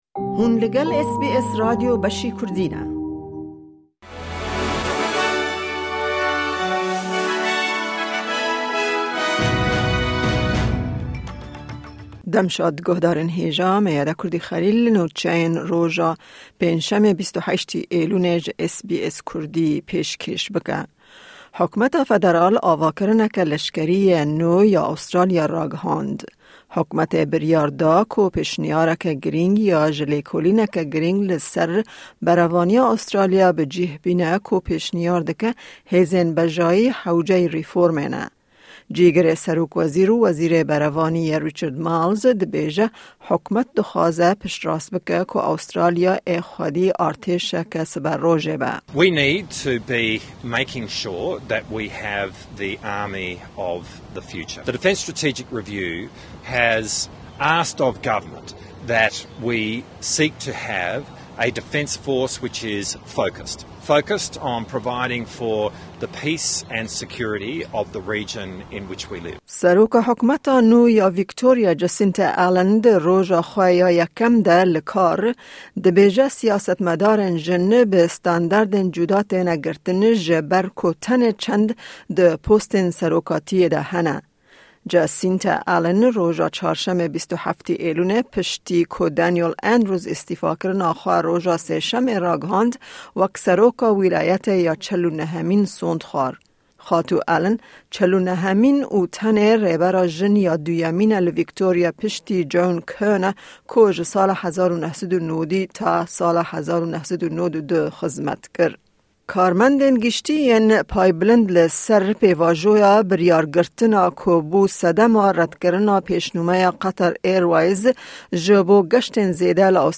Kurte Nûçeyên roja Pêncşemê 28î Îlona 2023